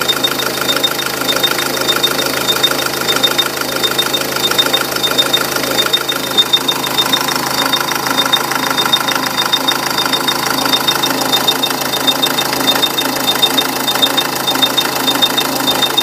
Piepgeluid bij stationair
Stationaire piep langs distributie zijde.Enkel bij warme motor.